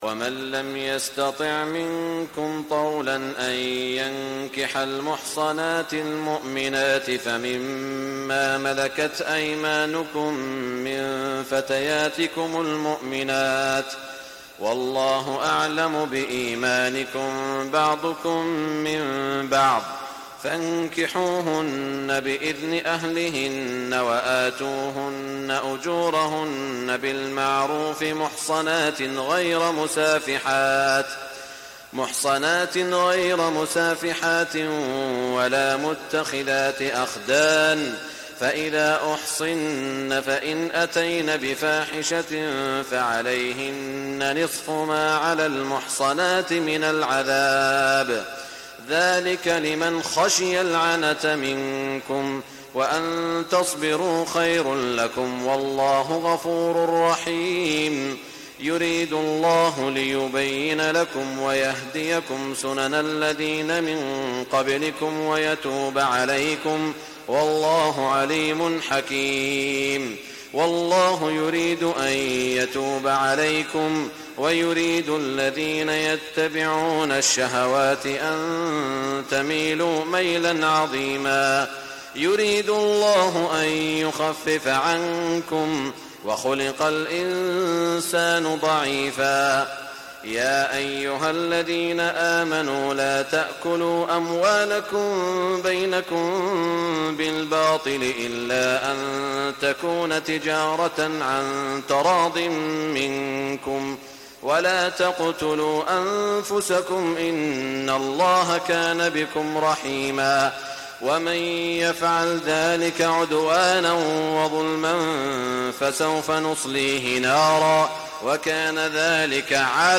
تهجد ليلة 25 رمضان 1420هـ من سورة النساء (25-99) Tahajjud 25 st night Ramadan 1420H from Surah An-Nisaa > تراويح الحرم المكي عام 1420 🕋 > التراويح - تلاوات الحرمين